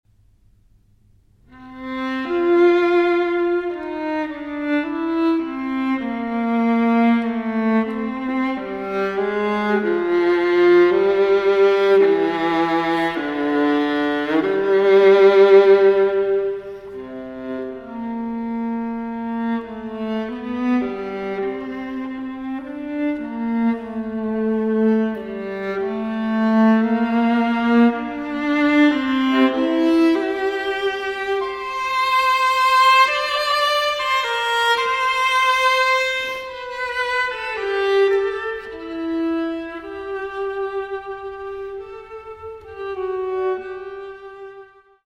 for Viola